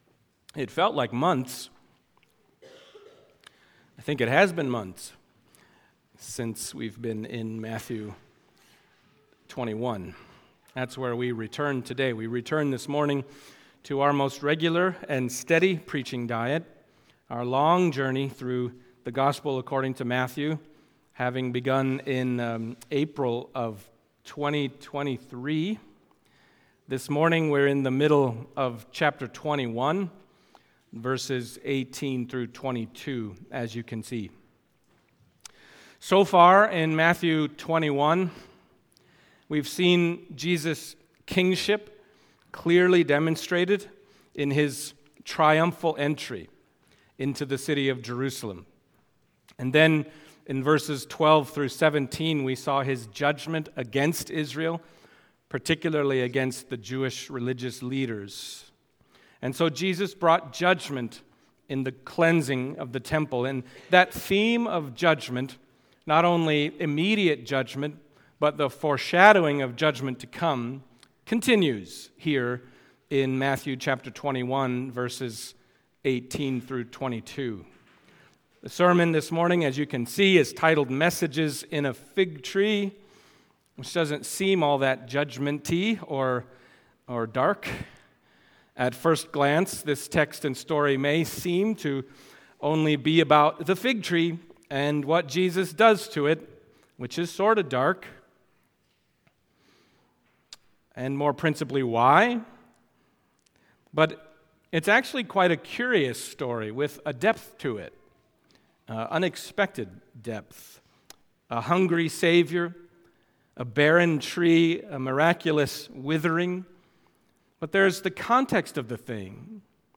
Matthew Passage: Matthew 21:18-22 Service Type: Sunday Morning Matthew 21:18-22 « Out of Egypt I Called My Son By What Authority?